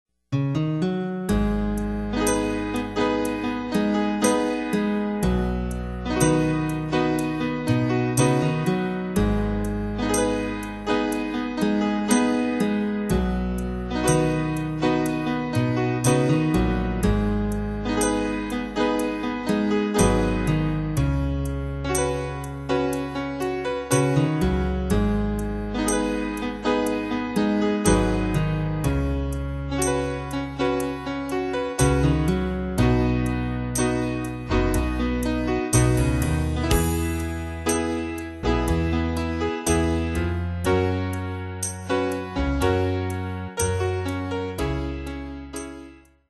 Fichiers Midi Et Audio
Pro Backing Tracks